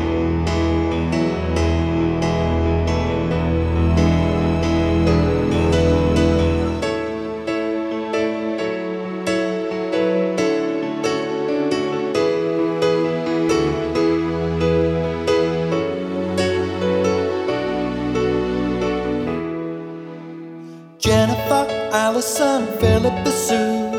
for solo male Pop (1990s) 4:05 Buy £1.50